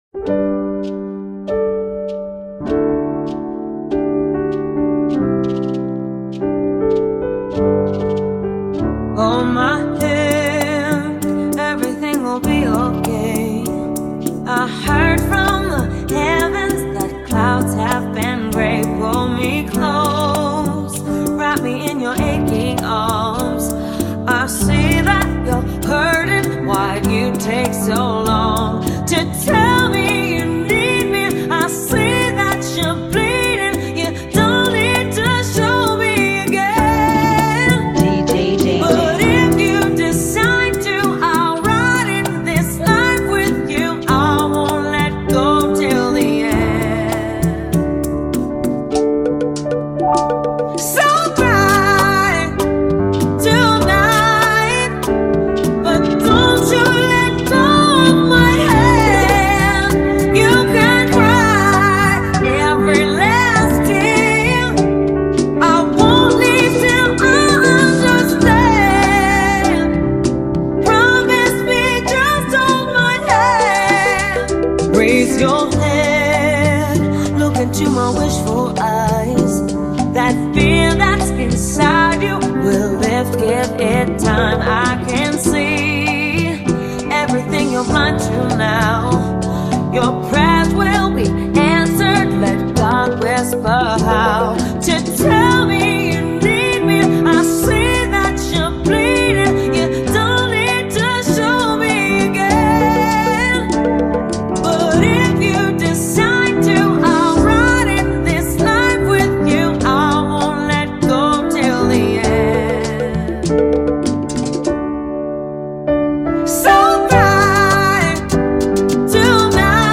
98 BPM
Genre: Bachata Remix